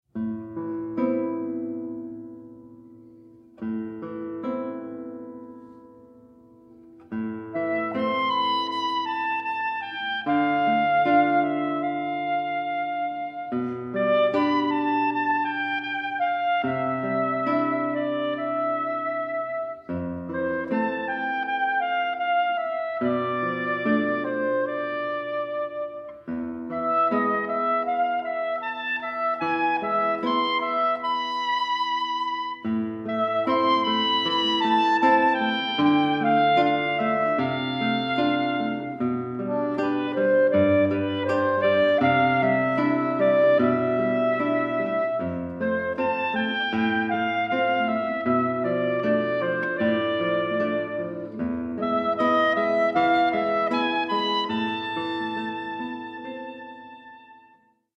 Stereo
saxophone
guitar